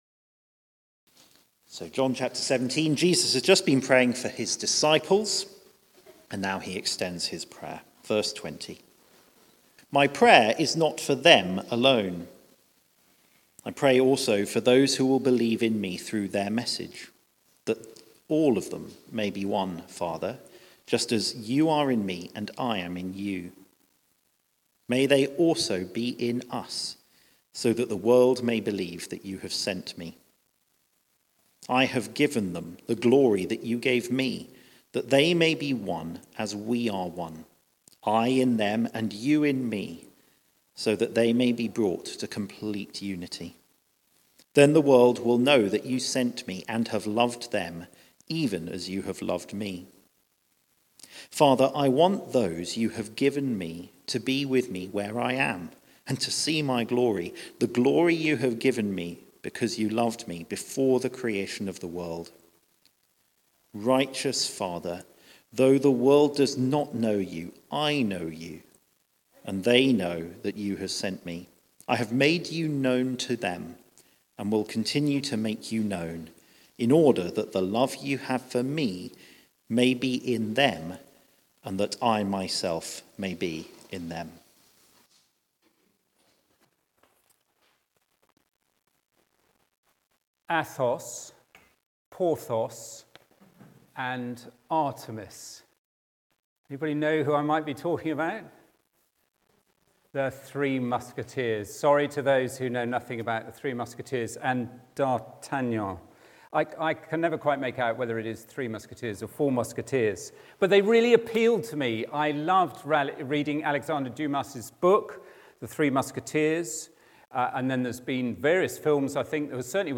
Theme: Jesus prays Sermon